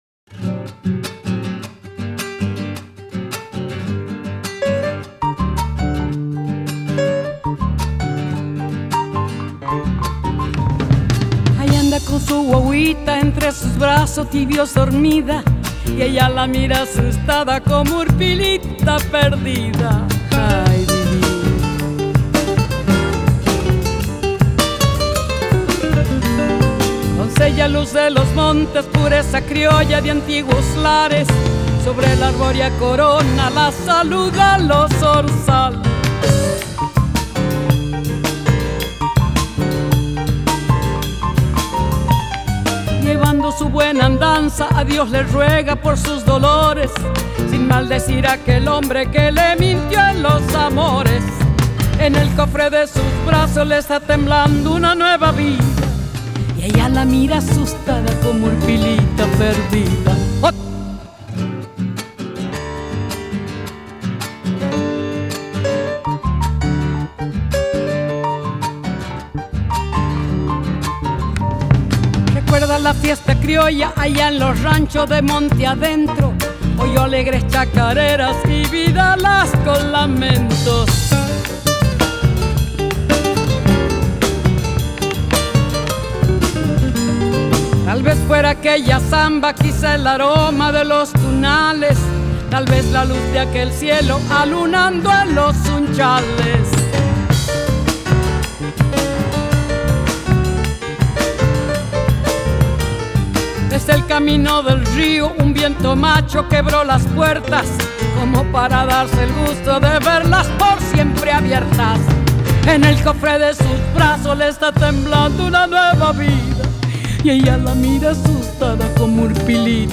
Жанр: Ethnic